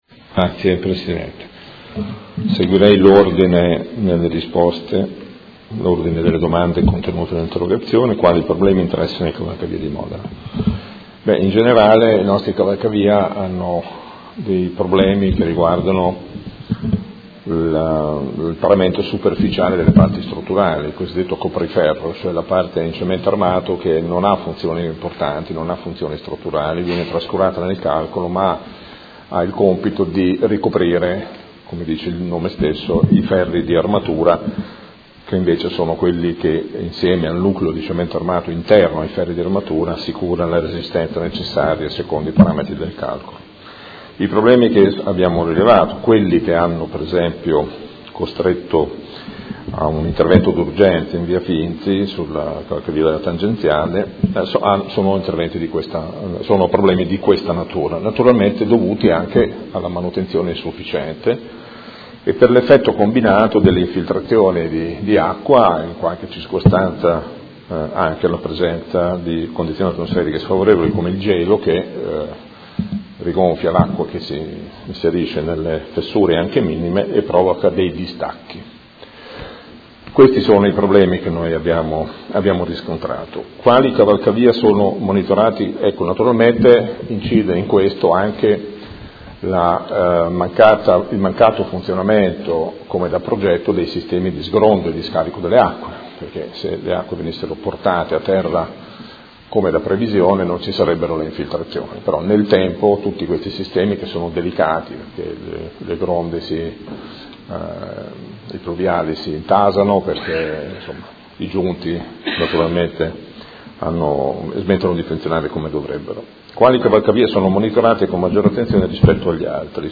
Seduta del 18/05/2017. Risponde a interrogazione dei Consiglieri Chincarini (Per Me Modena) e Arletti (PD) avente per oggetto: Qual è lo stato di sicurezza dei cavalcavia di competenza del Comune di Modena?